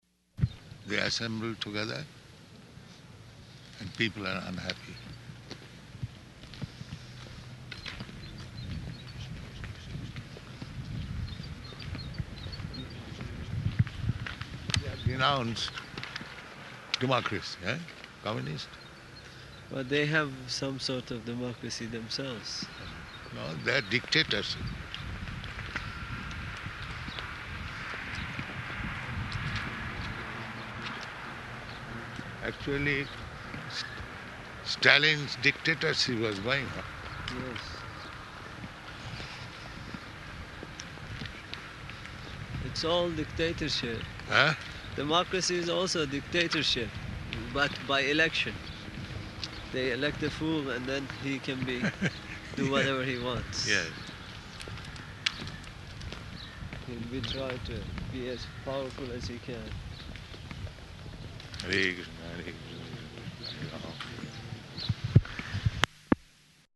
Morning Walk [partially recorded]
Type: Walk
Location: Tehran